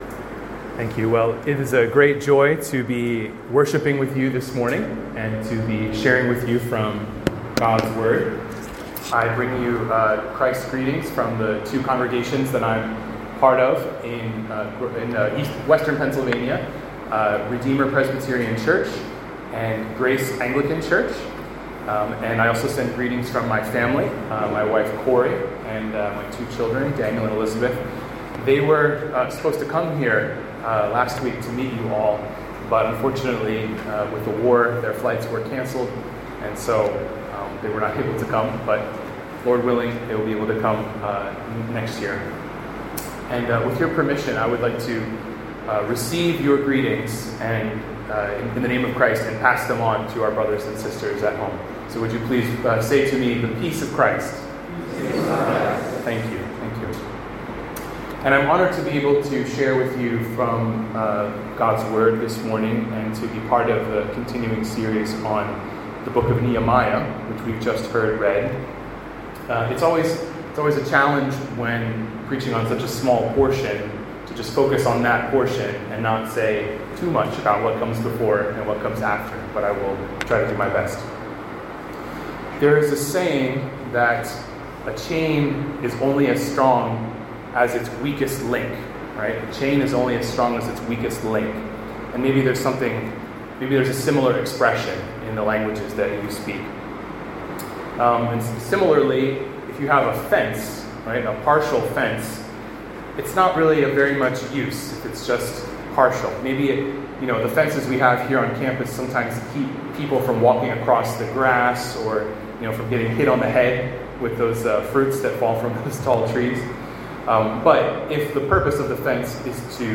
This is the audio (19:20, 8.9 MB) of a sermon I preached at a chapel service for students and faculty in Chennai, Tamil Nadu, on March 13, 2026. The Scripture text is Nehemiah 12:31–37, which was read before I stood up to speak so it's not on the recording.